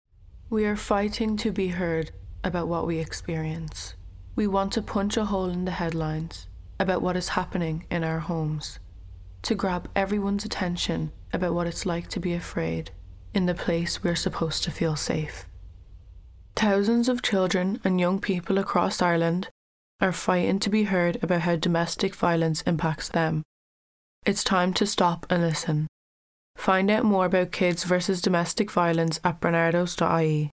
radio ad.
Kids-vs-Domestic-Violence_Radio-ad_2024.mp3